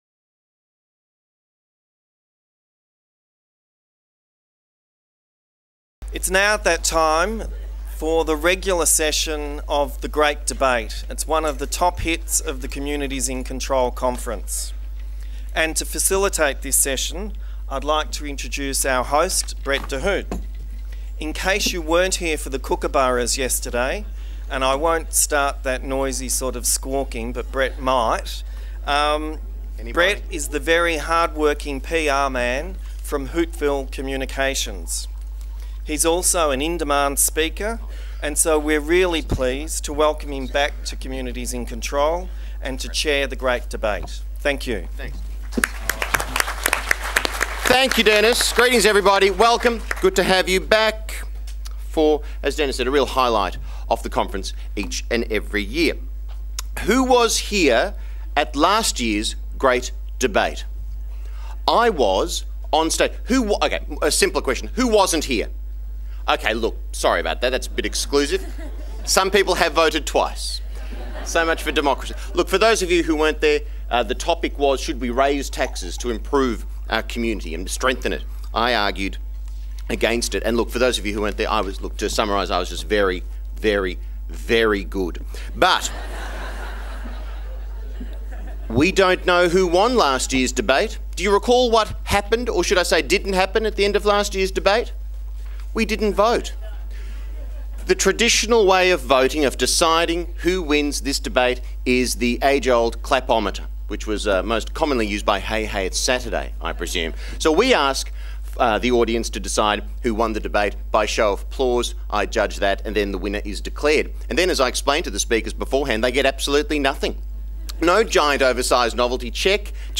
The Great Debate: Are Communities Out Of Control?